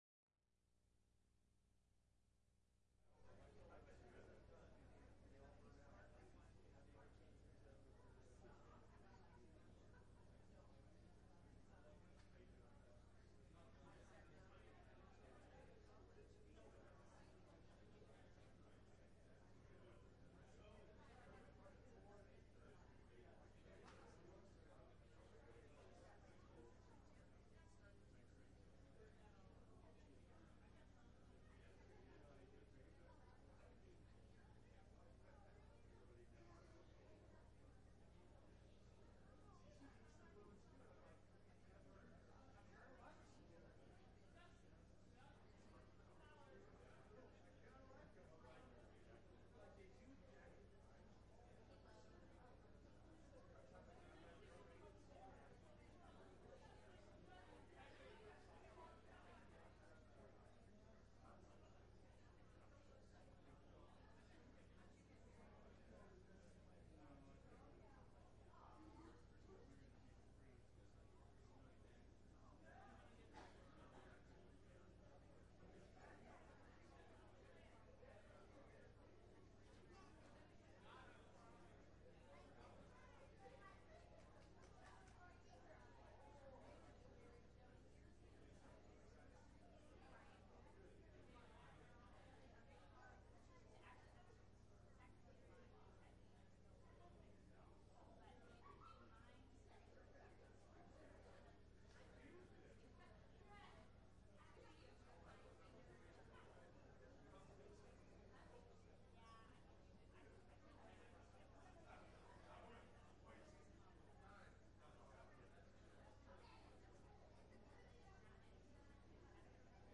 12-4_22-Sermon.mp3